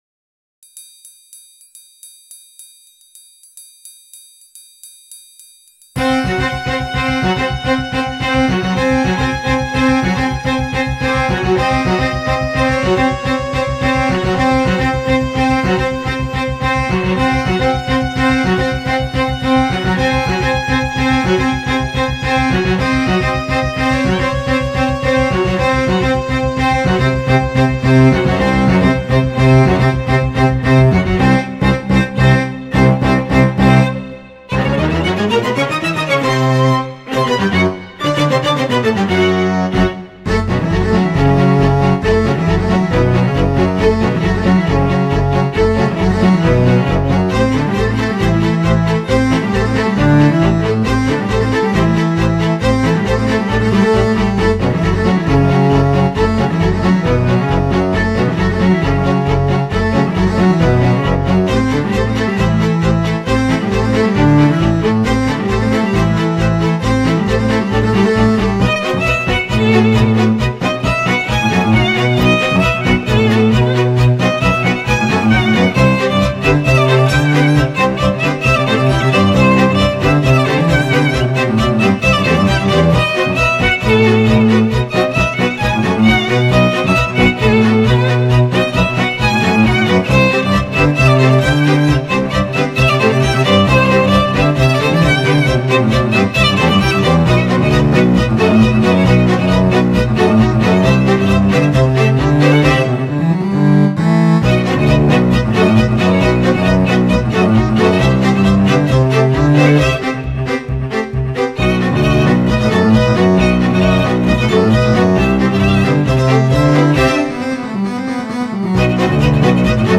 Another String Quartet
another-string-quartet.wma